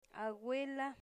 Phonological Representation a'gela